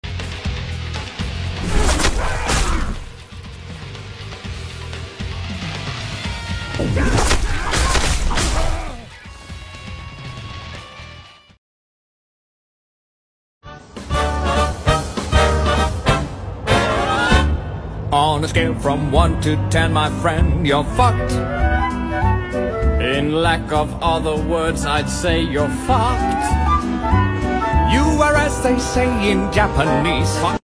Sindel's hair Wip move. Mortal sound effects free download
Mortal Mp3 Sound Effect Sindel's hair Wip move. Mortal Kombat.